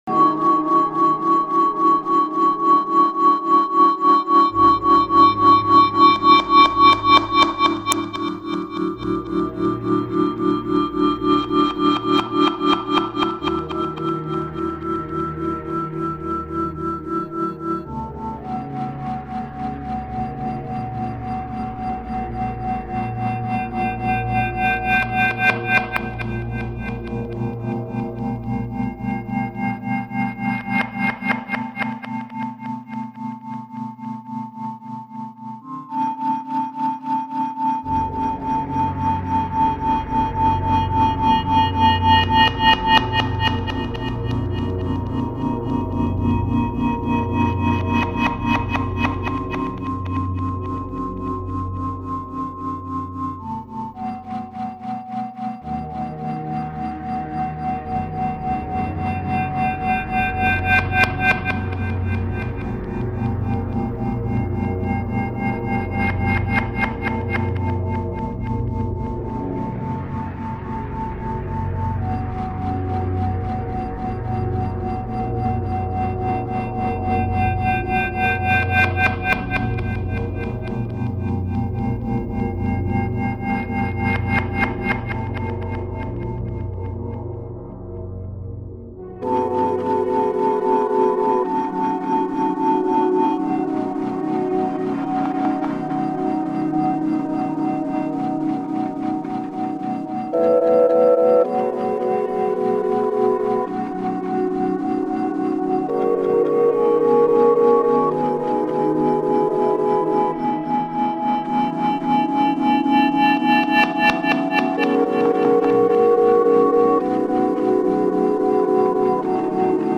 15 tracks, fifty minutes of soothing sounds.